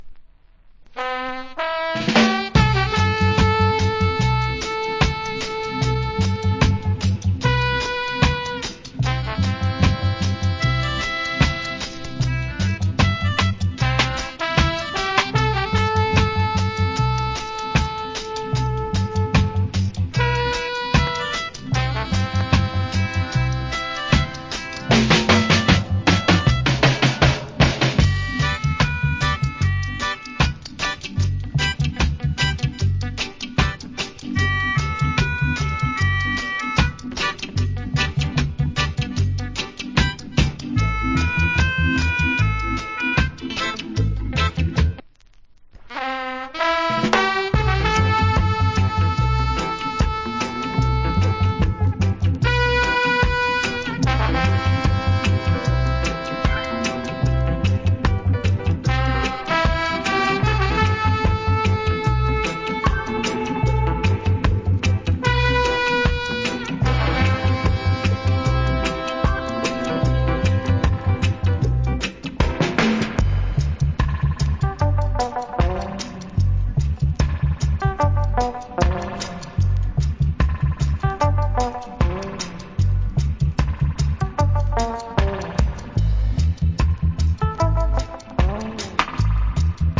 Killler Inst.